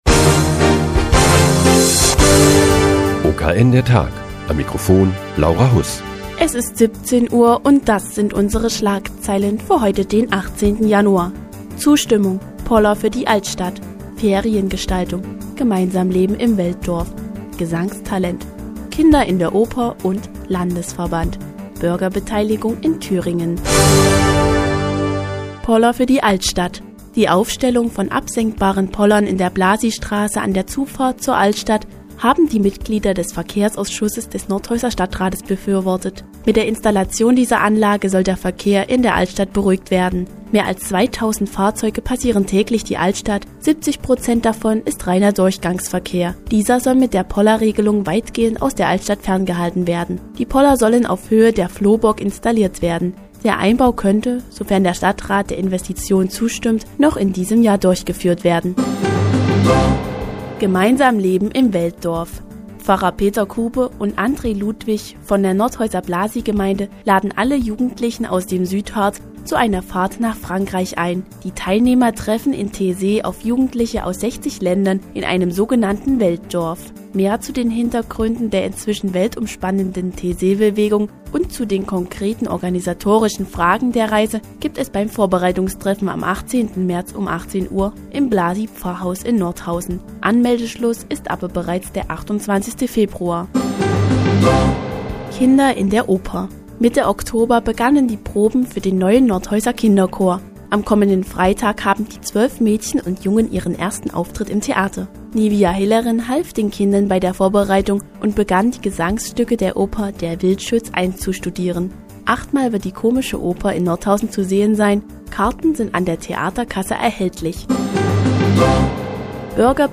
Die tägliche Nachrichtensendung des OKN ist nun auch in der nnz zu hören. Heute geht es um die Kinderoper "Der Wildschütz" und den Landesverband des Vereins "Mehr Demokratie e.V."